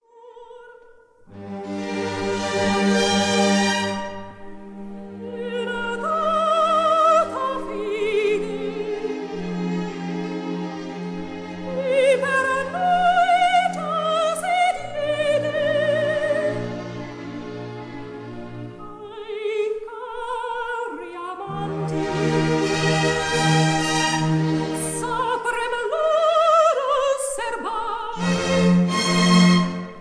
soprano
Recorded in Paris on 10 October 1955